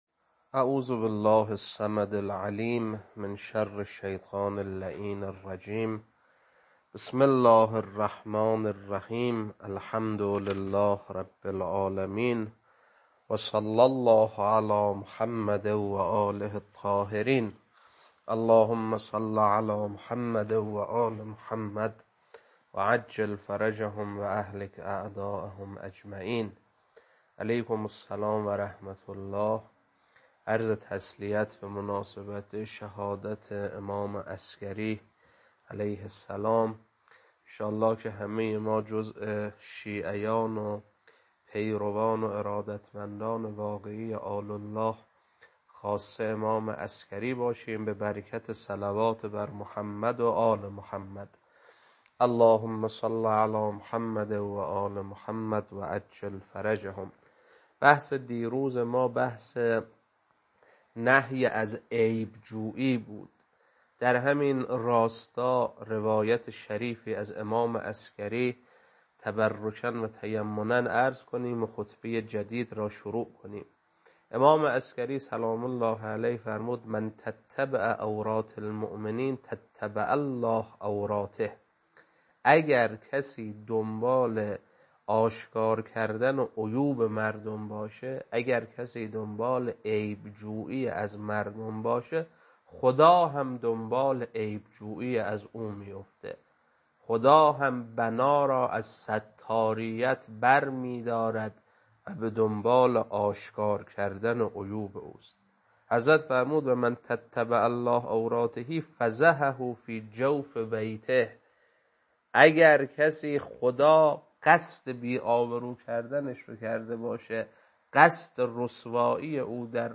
خطبه 141.mp3
خطبه-141.mp3